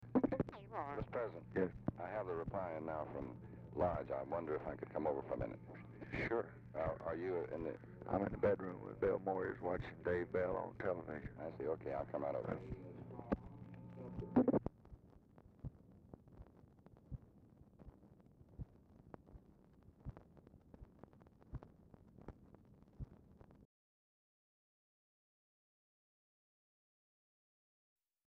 Telephone conversation # 9615, sound recording, LBJ and DEAN RUSK, 2/4/1966, 9:46AM | Discover LBJ
Format Dictation belt
Location Of Speaker 1 Mansion, White House, Washington, DC
Specific Item Type Telephone conversation